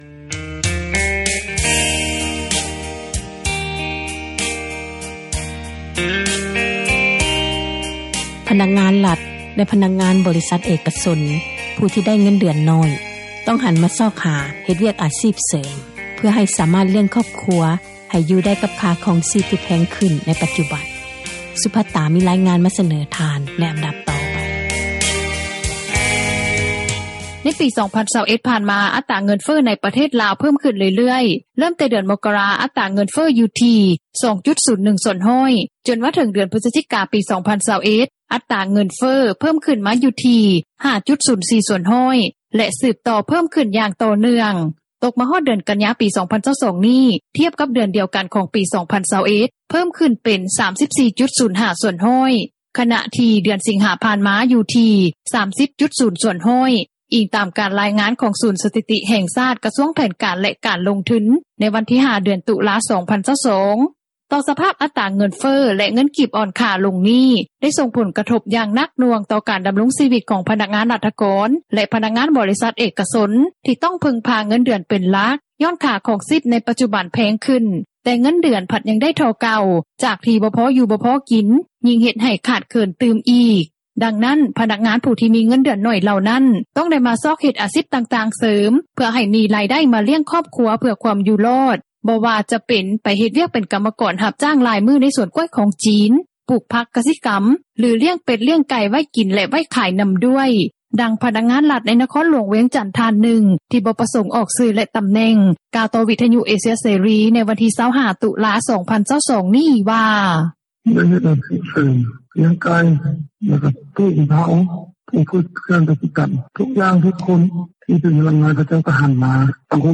ດັ່ງພະນັກງານຣັຖ ໃນນະຄອນຫຼວງວຽງຈັນ ທ່ານນຶ່ງ ທີ່ບໍ່ປະສົງອອກຊື່ ແລະຕໍາແໜ່ງ ກ່າວຕໍ່ວິທຍຸ ເອເຊັຽເສຣີ ໃນວັນທີ 25 ຕຸລາ 2022 ນີ້ວ່າ: